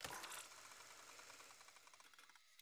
SD_SFX_GrapplingHook_Coiled.wav